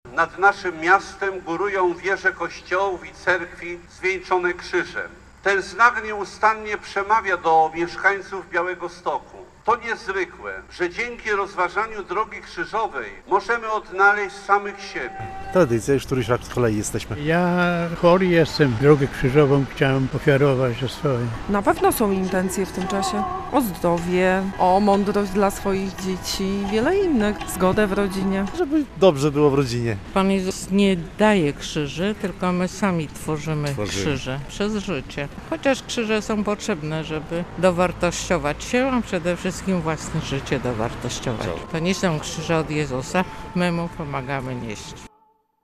Pochód poprzedziła modlitwa metropolity białostockiego arcybiskupa Józefa Guzdka